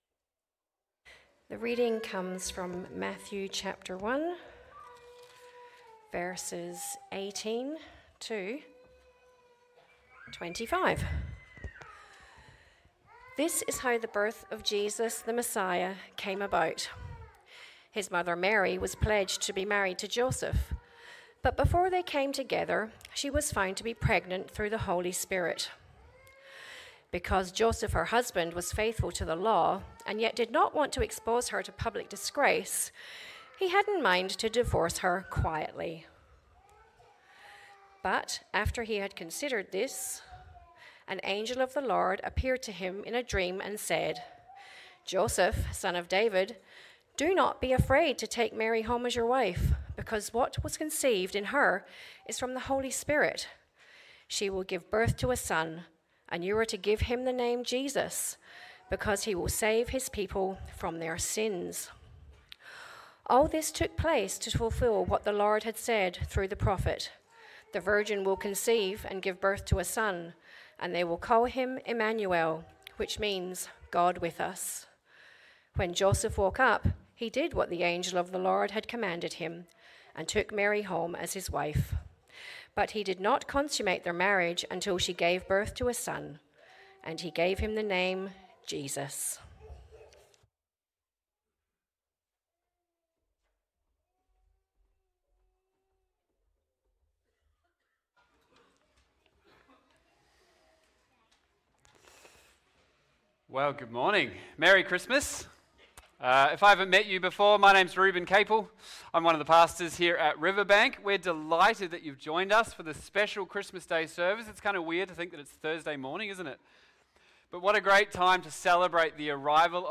Sermons | Riverbank Christian Church